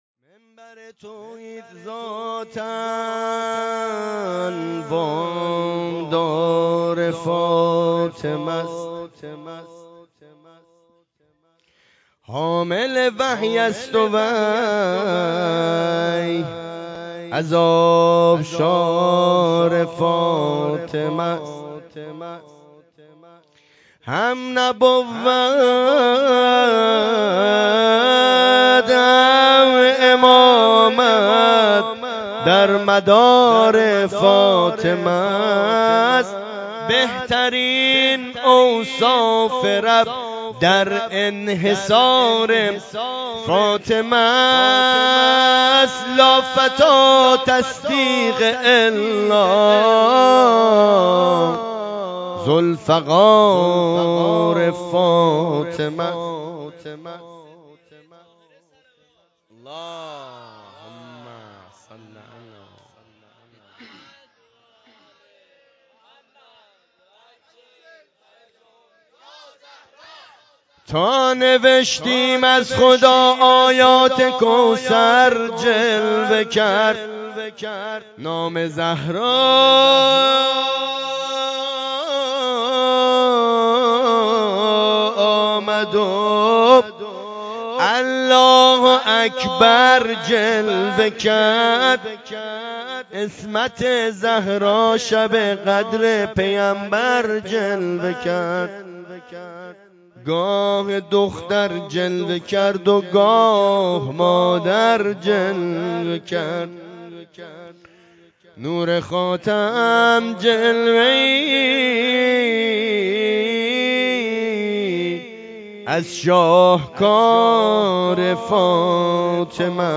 ولادت حضرت زهرا و بزرگداشت مقام مادر و زن1403